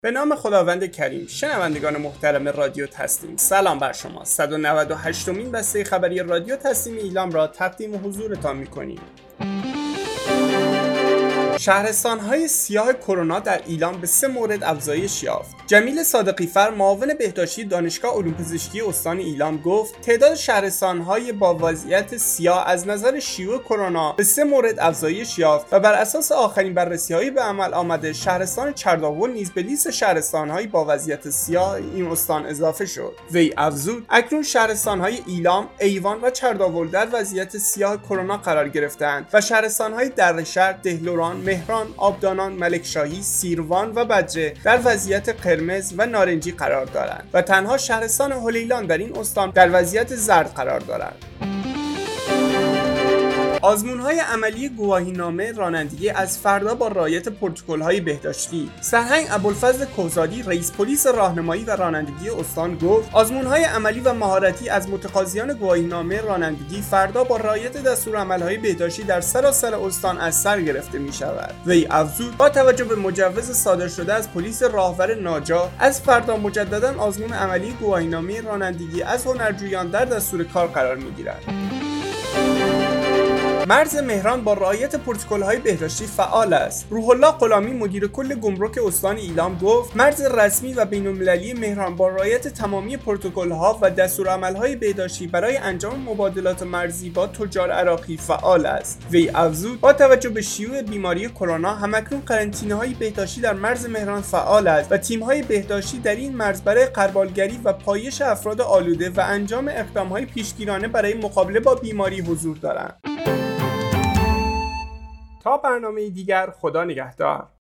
گروه استان‌ها- آخرین و مهمترین اخبار استان ایلام در قالب بسته خبری